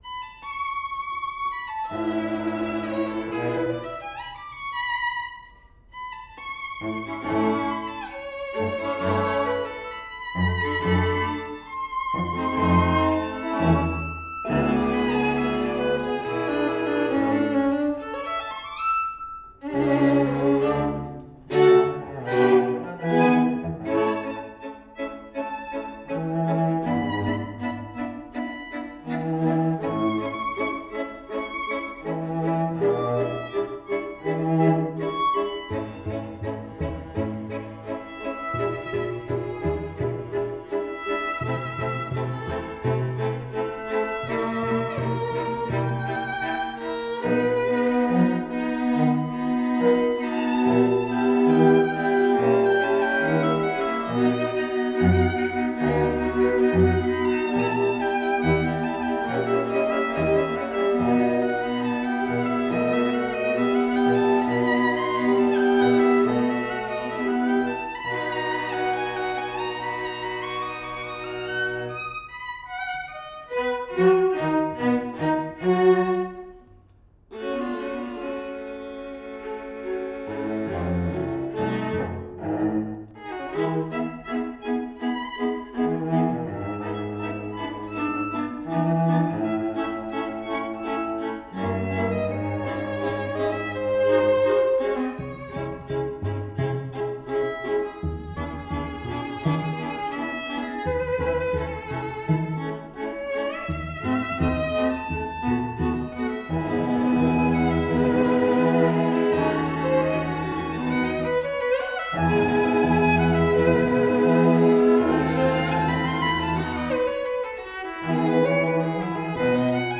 Soundbite 1st Movt
For 2 Violins, 2 Violas and Cello